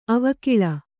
♪ avakiḷa